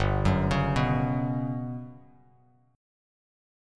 GM#11 chord